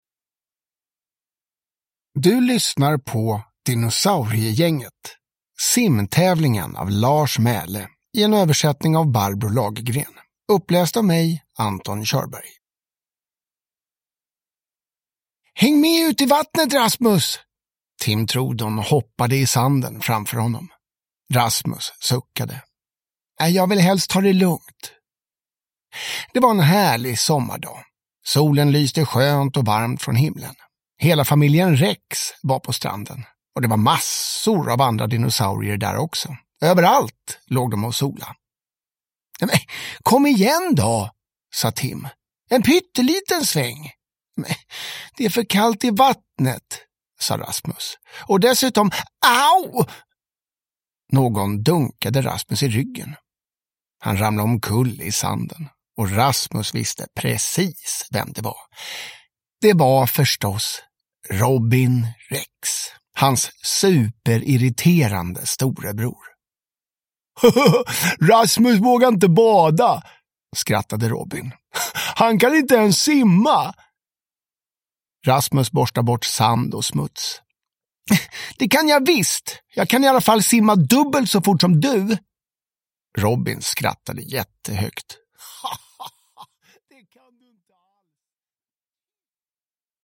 Simtävlingen (ljudbok) av Lars Mæhle